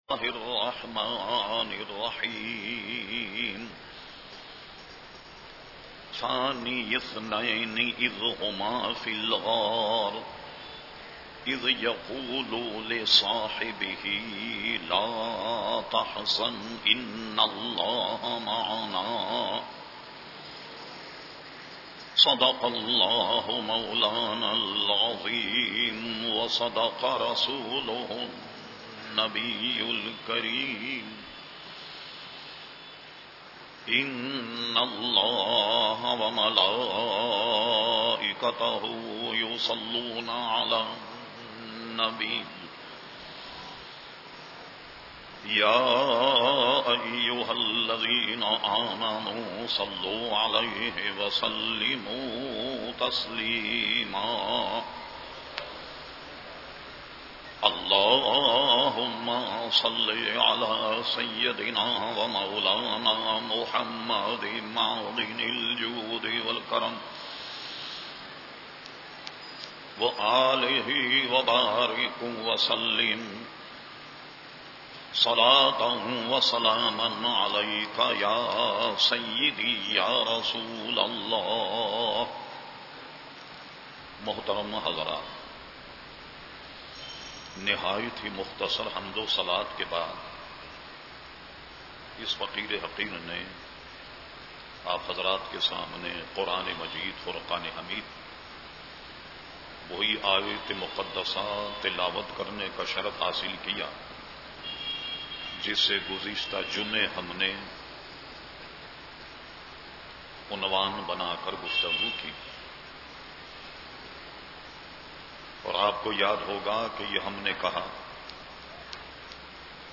At Memon Masjid Muslehuddin Garden Karachi on 27 June 2008
Category : Speeches | Language : Urdu